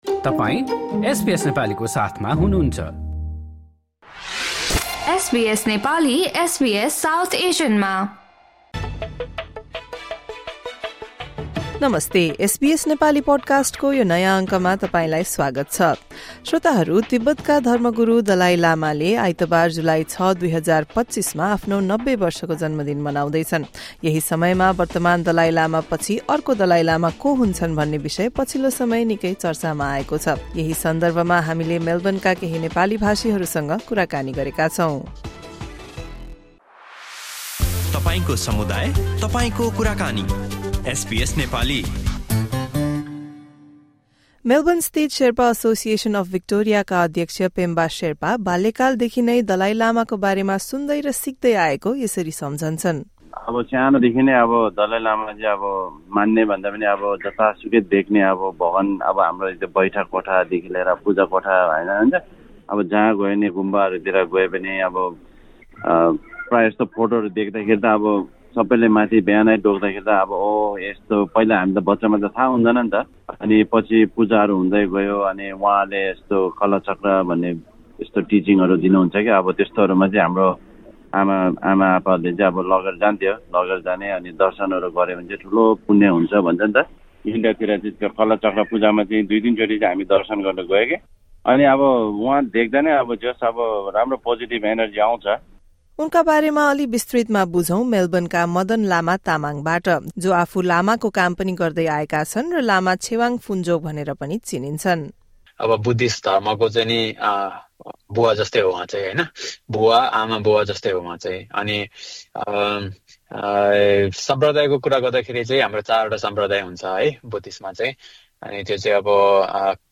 एसबीएस नेपालीसँग गरेको कुराकानी सुन्नुहोस्।